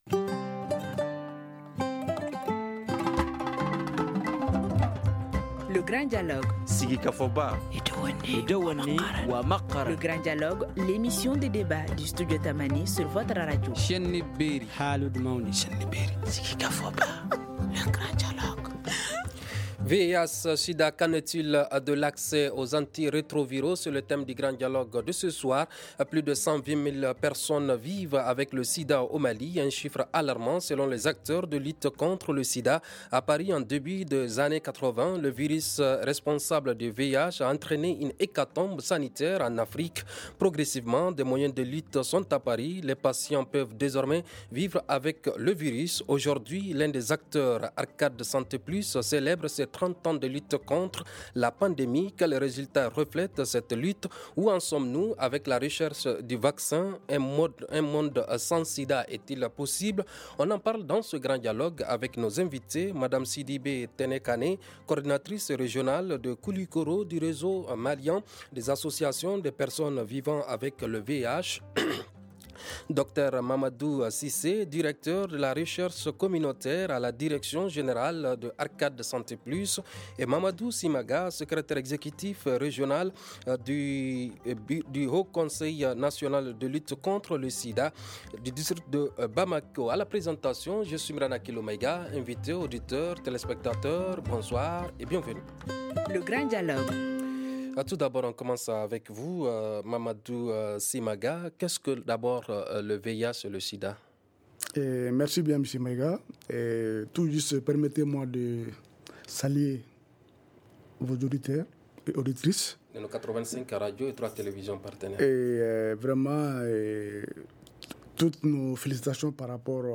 On en parle dans ce Grand Dialogue avec nos invités :